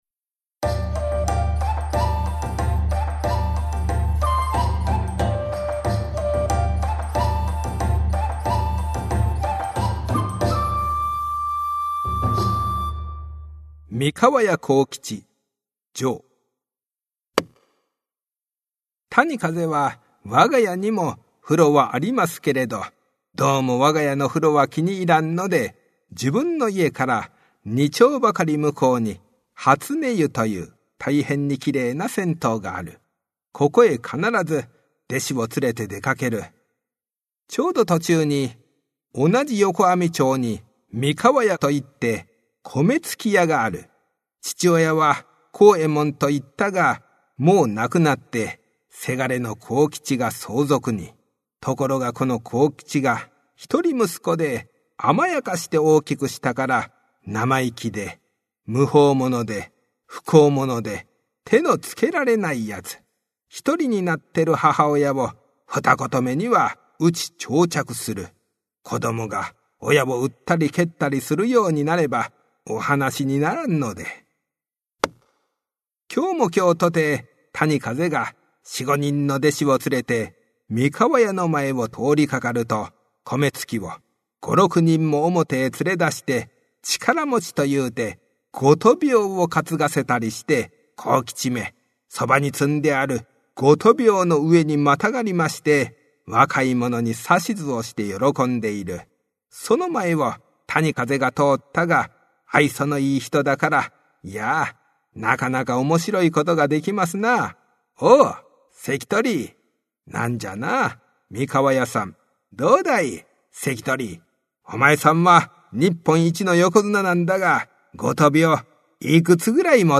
[オーディオブック] こども講談 十四
近頃、注目を浴びているのが日本の伝統話芸「講談」。